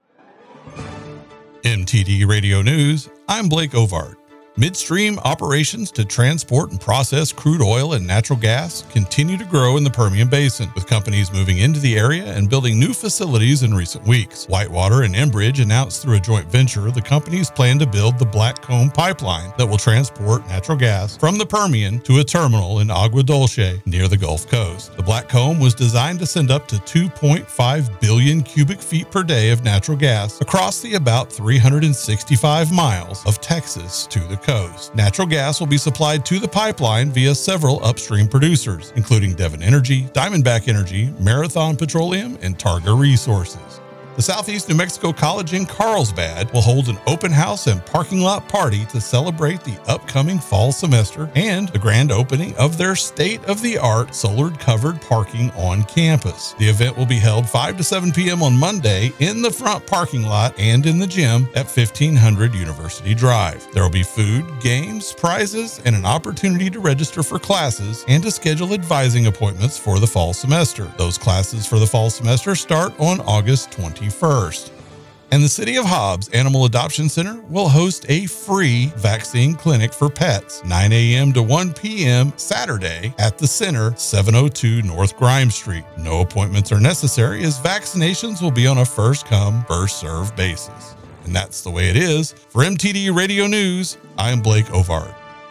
W105 NEWS AUGUST 16, 2024